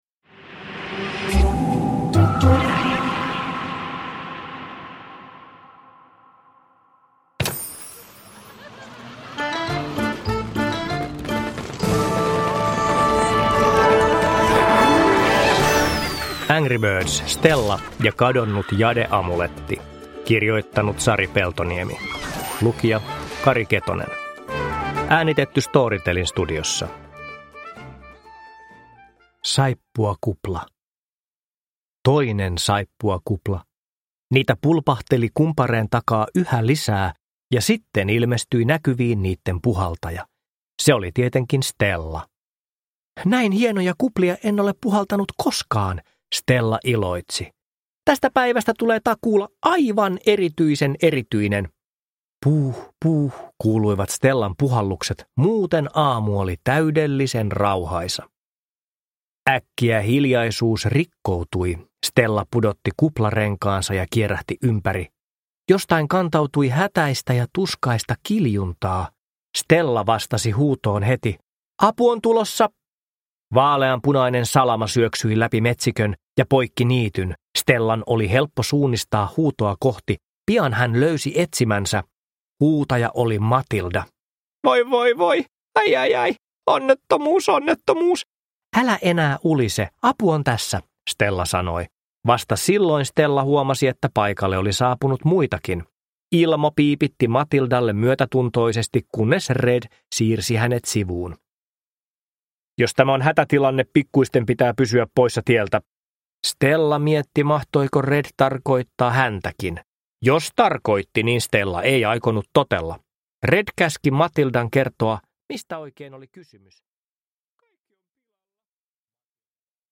Angry Birds: Stella ja kadonnut jadeamuletti – Ljudbok – Laddas ner